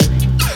Ragga_Stab.wav